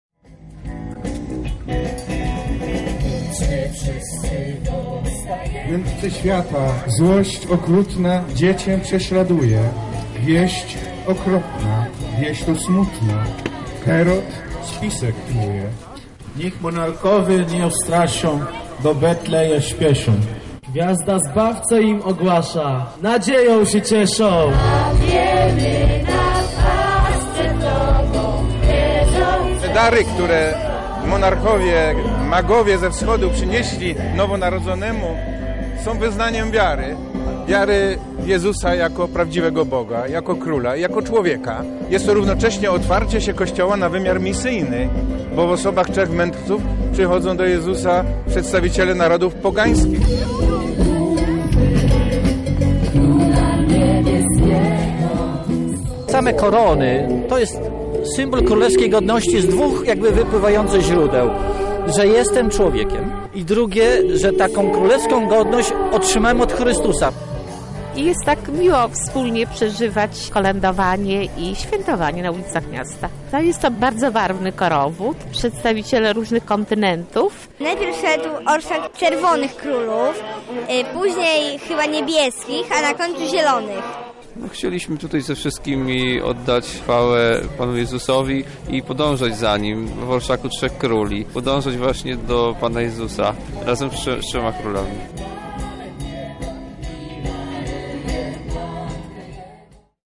W naszym mieście kolorowy pochód przeszedł spod Zamku Lubelskiego, aż do Katedry.